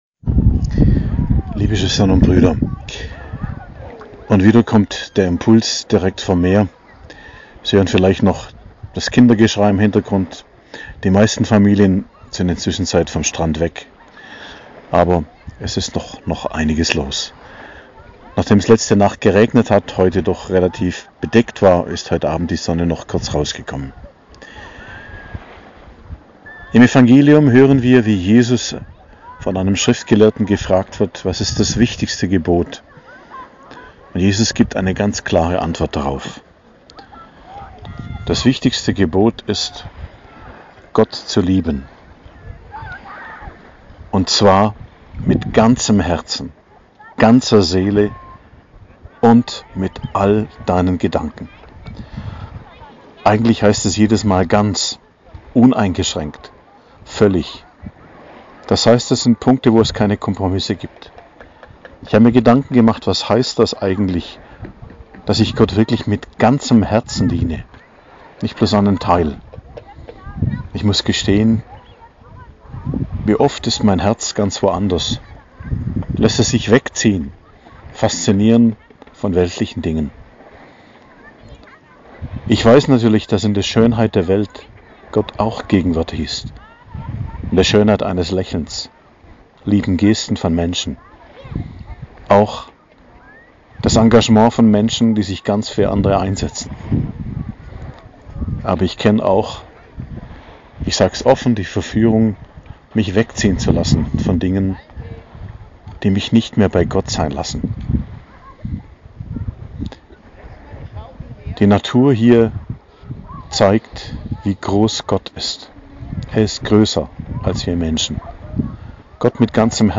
Predigt am Freitag der 20. Woche i.J., 19.08.2022